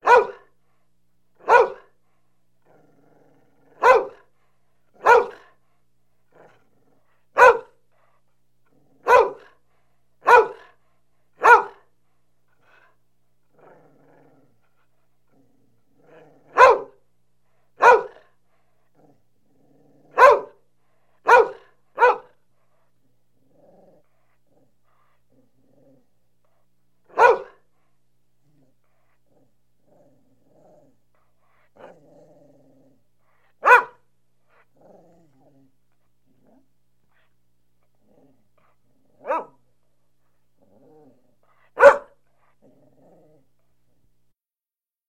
the-sound-of-a-barking-dog